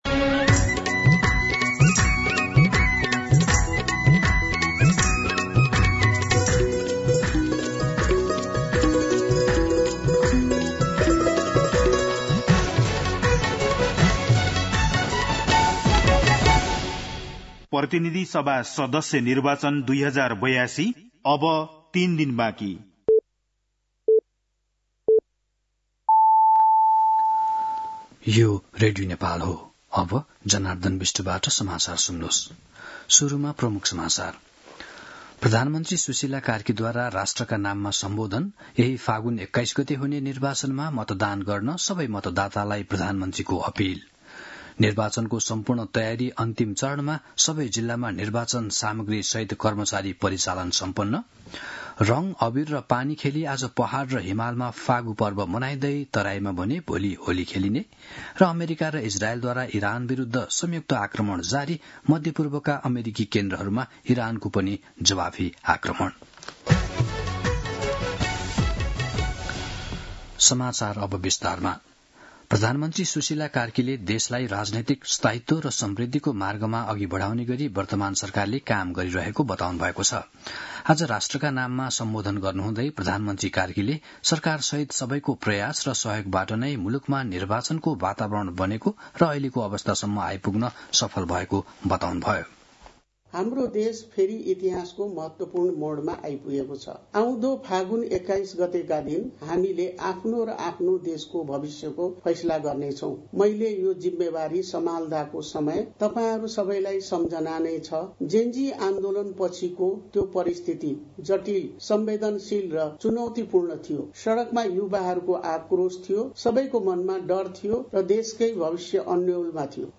दिउँसो ३ बजेको नेपाली समाचार : १८ फागुन , २०८२